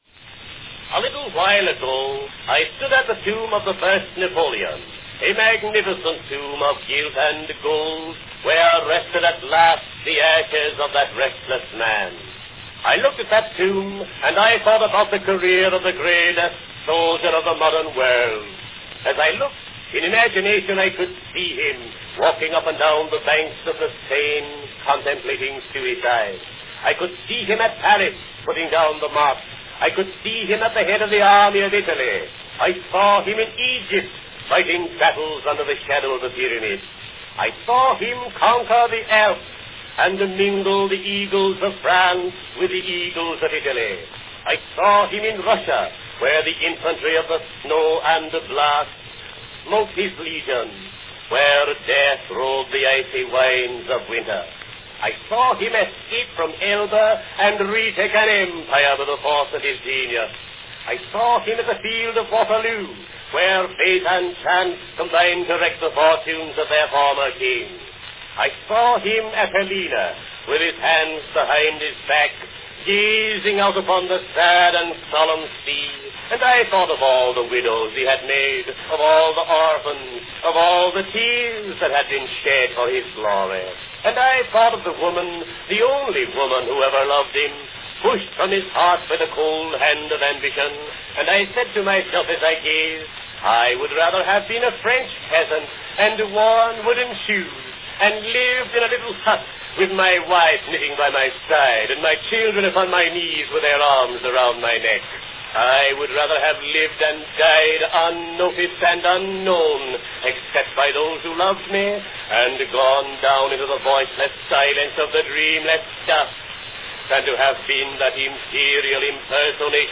A 1902 recitation of Robert Ingersoll's powerful reflection After Visiting the Tomb of Napoleon, by Len Spencer.
Category Talking
Performed by Len Spencer
Announcement None
If it is a re-make, because of the lack of an announcement, this version may date from around 1909.